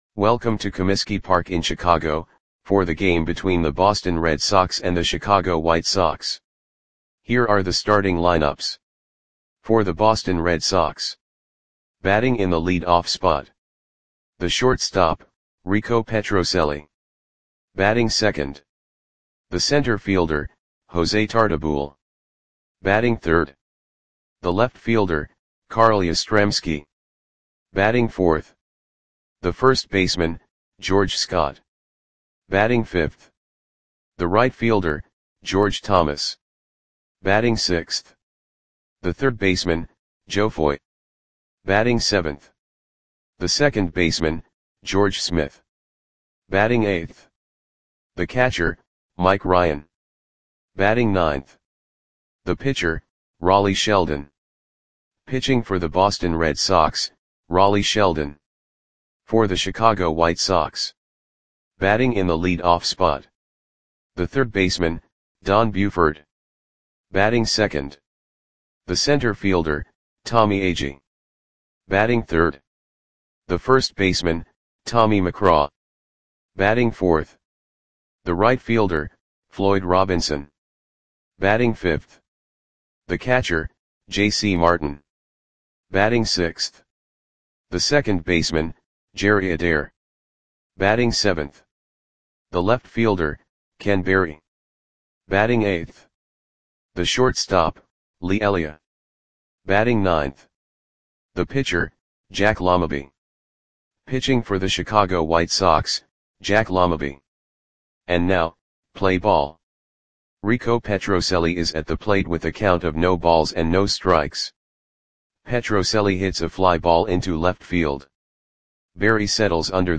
Audio Play-by-Play for Chicago White Sox on July 3, 1966